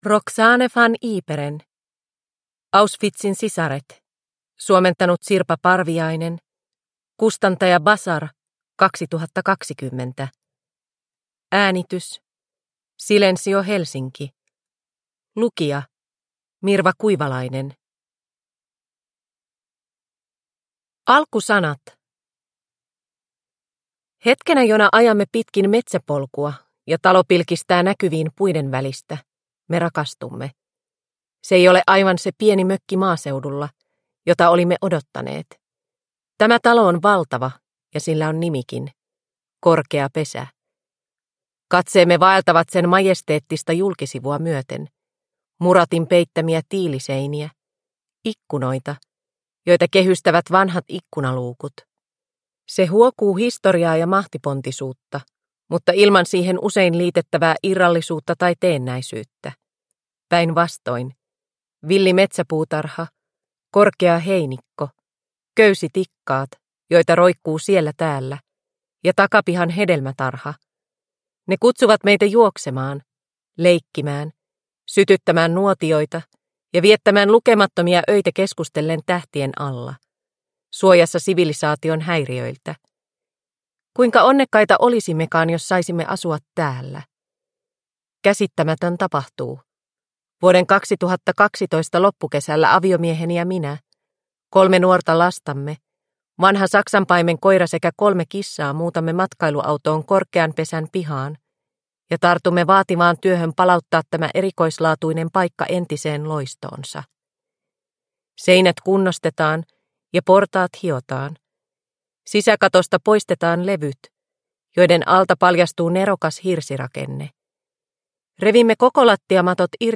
Auschwitzin sisaret – Ljudbok – Laddas ner